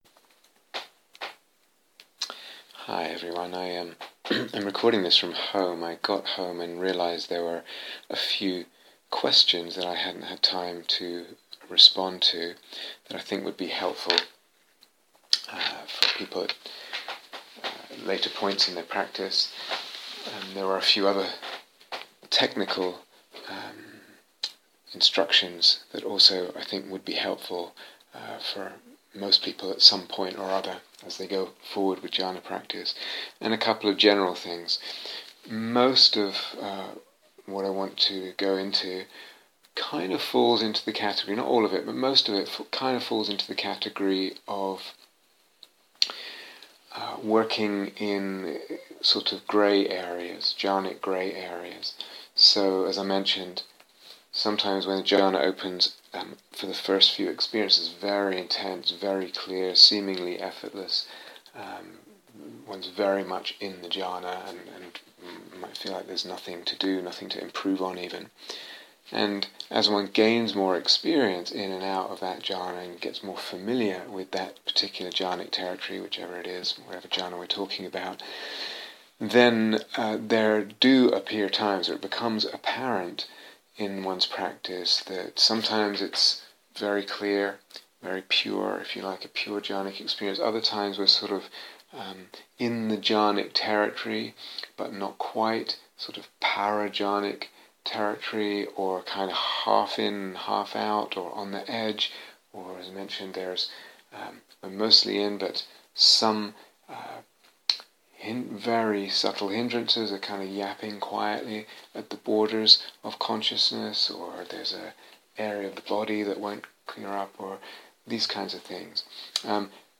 I'm recording this from home.